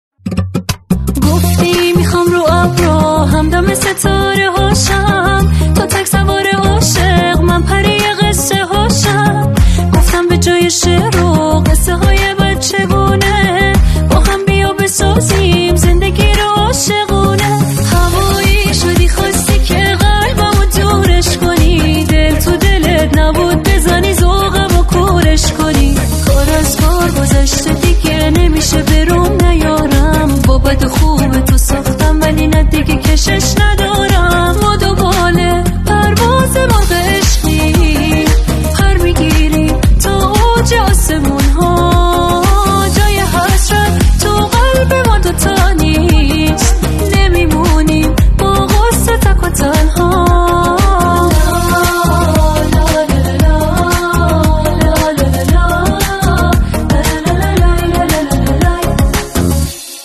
با صدای دختر